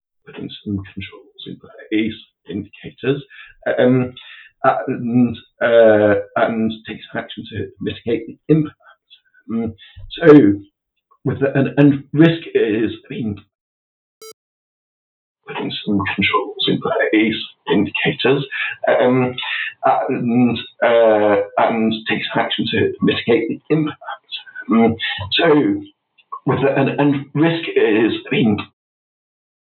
Removing voice stuttering and equalizing voice volume
I completed a 60 minute interview with a gent who has an accent, a minor stutter and major voice volume issues. (The volume stays normal for a while, then goes very very soft and then very loud) Listening is a chore.
However the voice tone is garbling words and the stutter is distracting.
Here is a small sample of the recording I am playing with